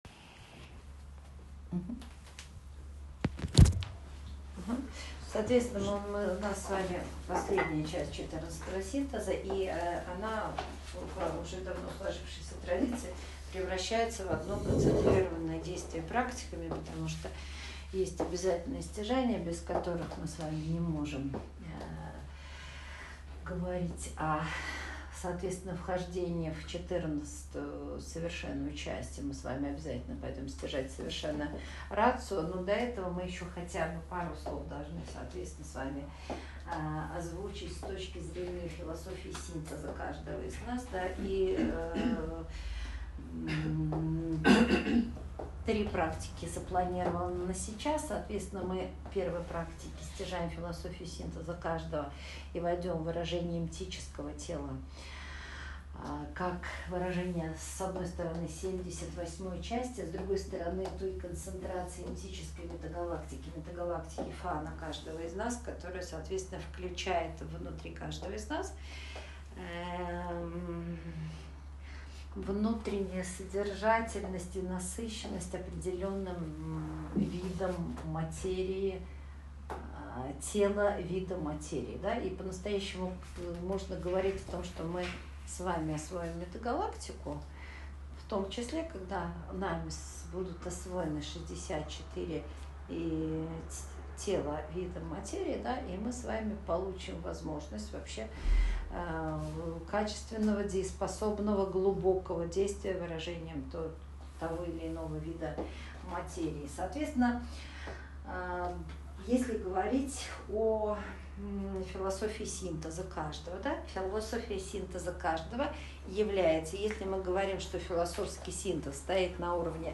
Слушать/смотреть Аудио/видео скачать Часть 1: Часть 2: Часть 3: Часть 4: Часть 1: Аудио Часть 2: Аудио Часть 3: Аудио Часть 4: Аудио 4 Часть (записалась не полностью, всего 8 минут).